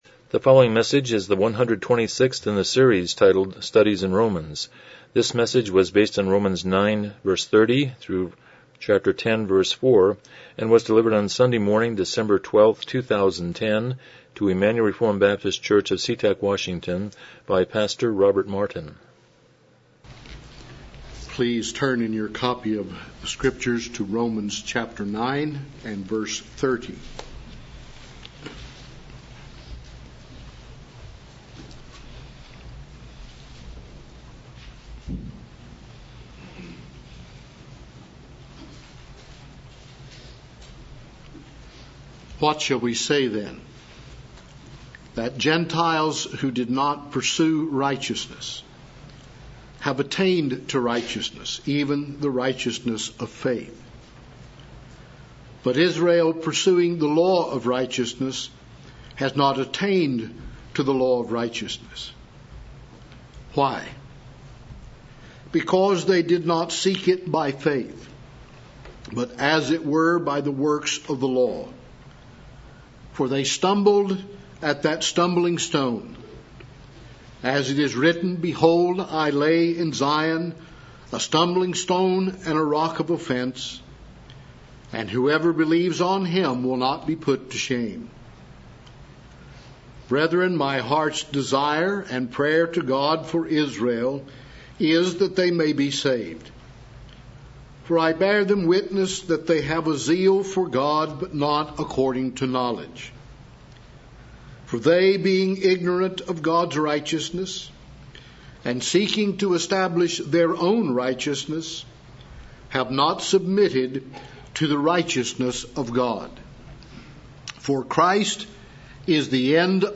Romans 9:30-10:4 Service Type: Morning Worship « 115 Chapter 22.7